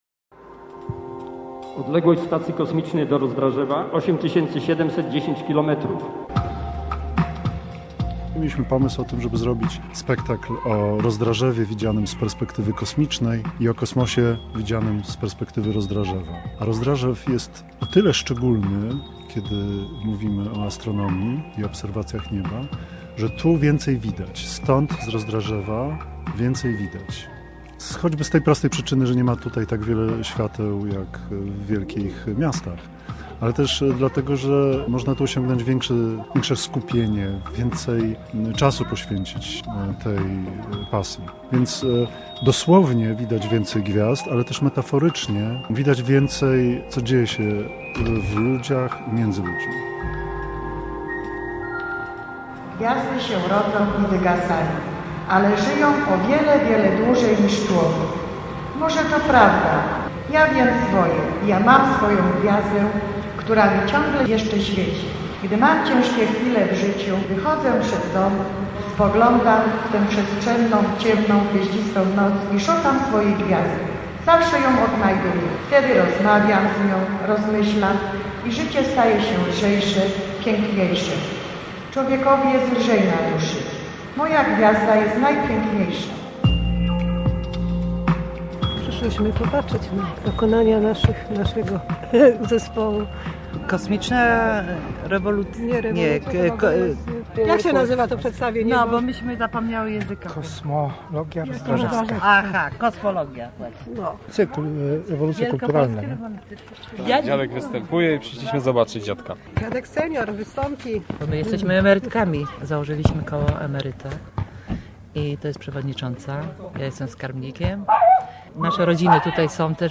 Stąd więcej widać - reportaż